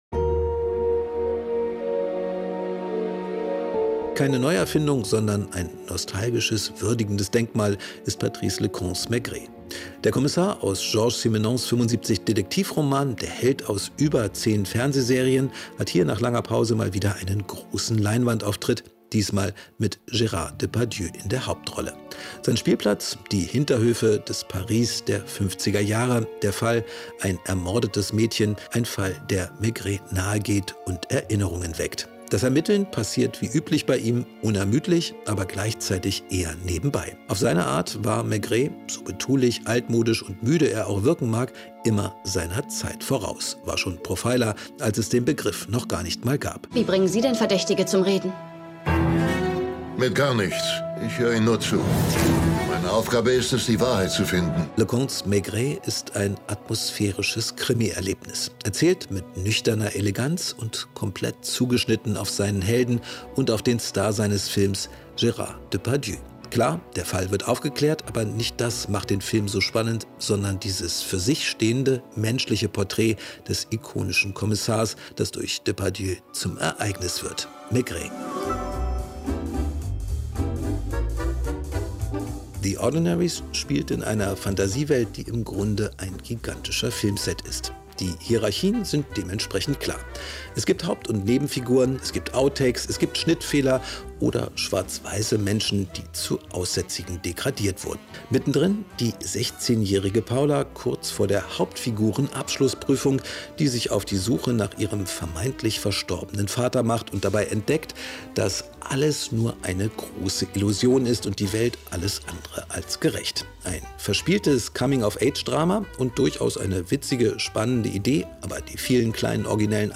Inforadio Nachrichten, 30.03.2023, 06:40 Uhr - 30.03.2023